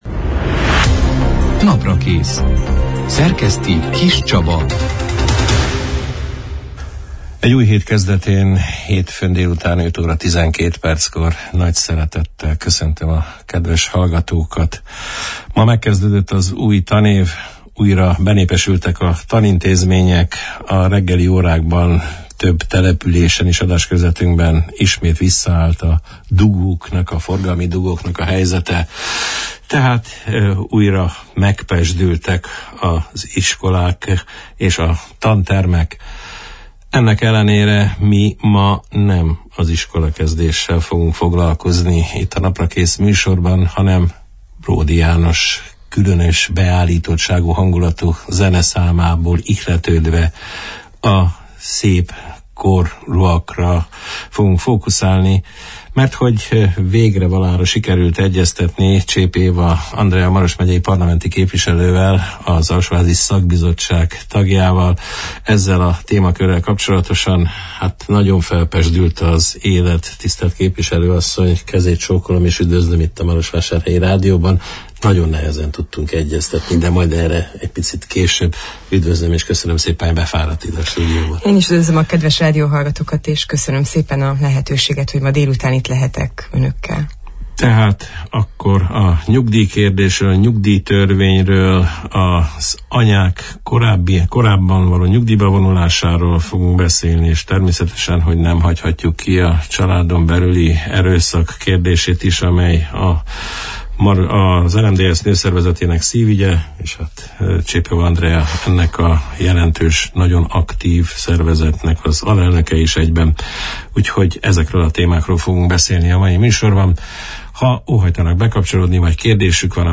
A vita alatt álló nyugdíjtörvényről, a magán nyugdíjalapok helyzetéről, a gyeremekgondozási pótlék felső határának megállapítási kritériumairól, a három gyermekes anyák esetleges korábbi nyugdíjba vonulásának esélyeiről, a nők elleni erőszak áldozatainak megsegítéséről beszélgettünk a szeptember 11 – én, hétfőn elhangzott Naprakész műsorban Csép Éva – Andrea Maros megyei parlamenti képviselővel.